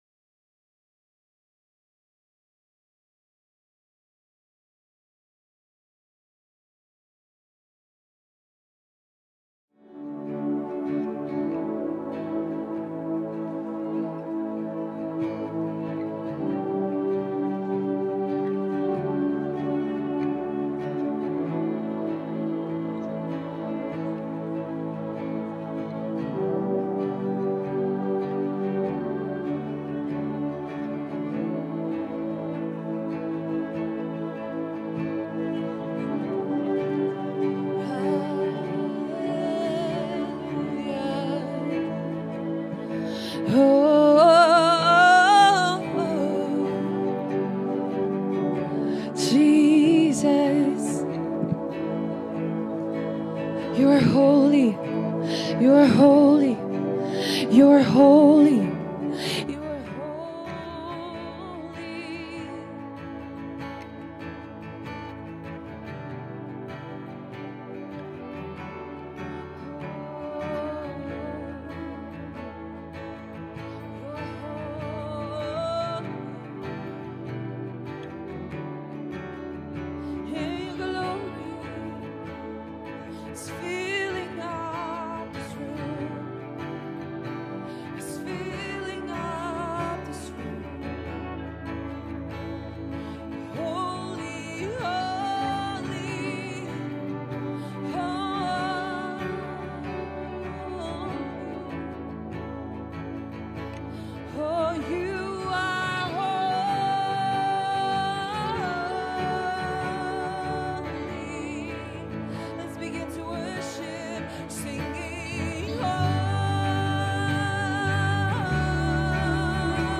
TC Band Live Worship (May 19, 2019).mp3